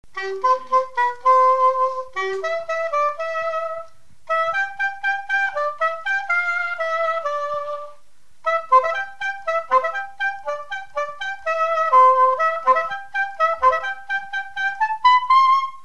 Galop
Résumé instrumental
Répertoire de musique traditionnelle